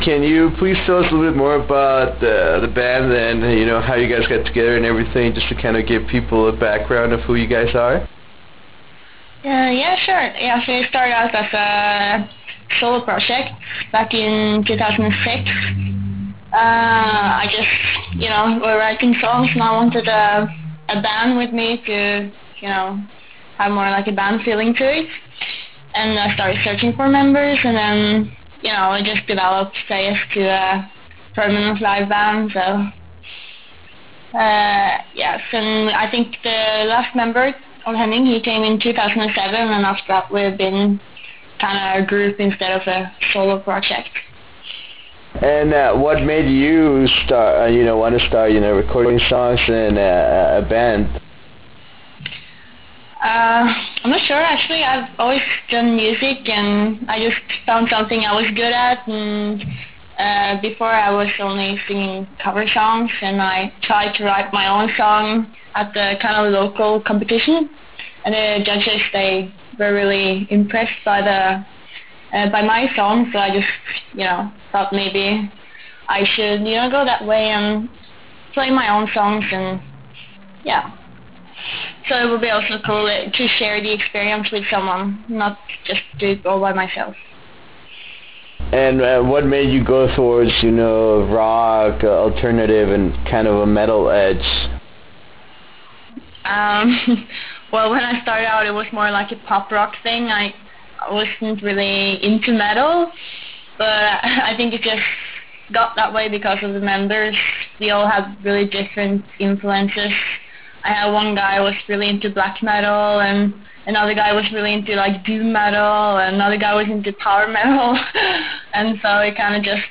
We also talk about the band's future plans and when the new full-length release will be coming out. To listen to this 15 minute interview, please click HERE or Right Click and select Save As to take this interview with you.